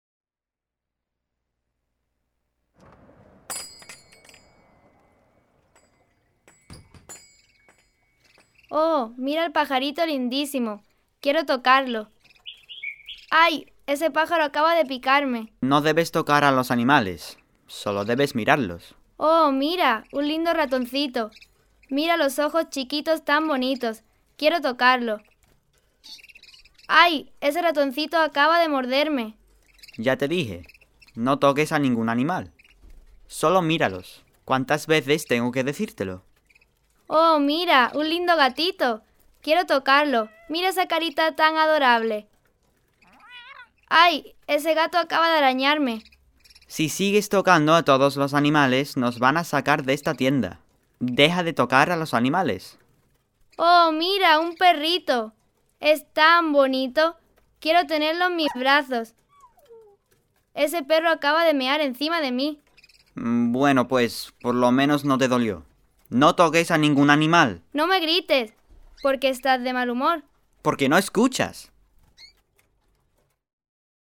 Whimsical Dialogues for Upper Level Classes
The Spanish enactment (mp3) can be played while the students read along.